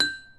snd_elevator_power_down
bell chime ding dong elevator ping ring sound effect free sound royalty free Sound Effects